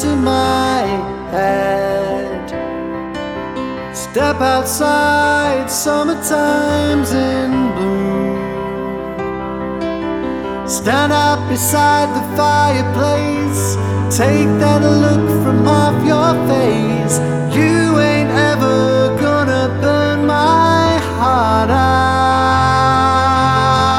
Up 2 Female Key